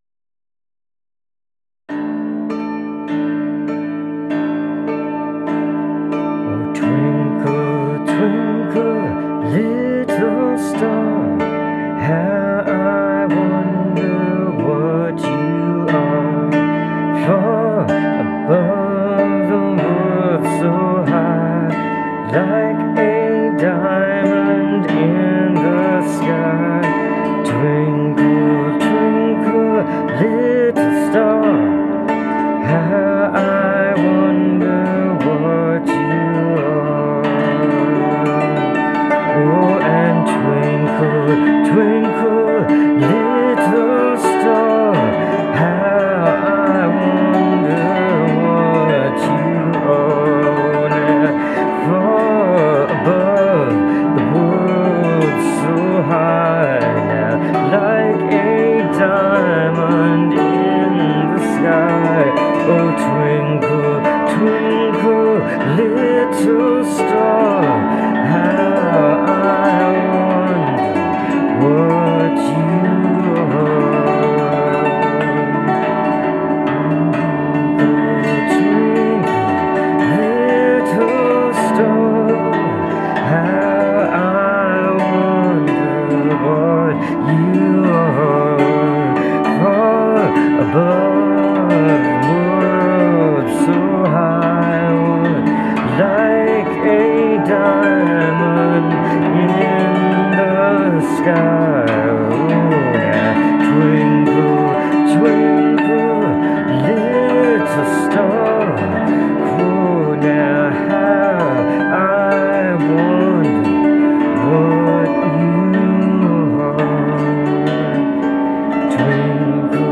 Vocals and Electric Dulcimer